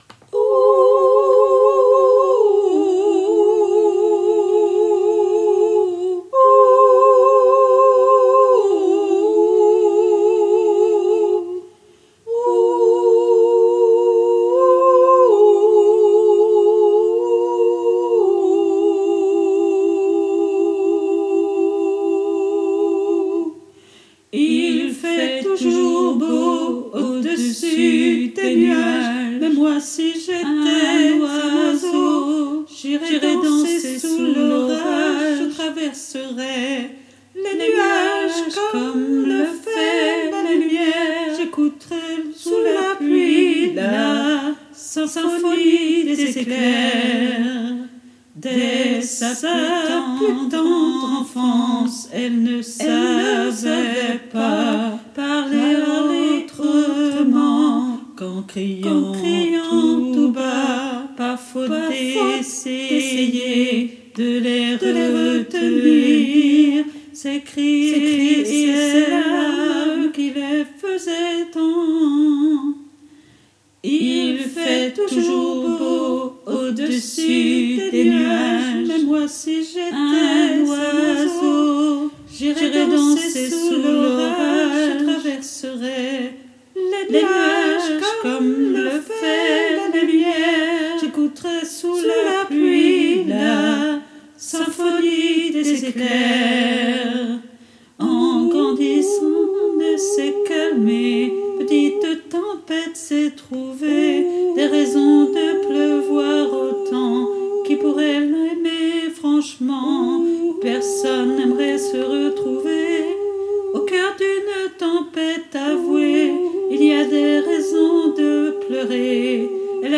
MP3 versions chantées (les audios peuvent être téléchargés)
Tutti (2 voix)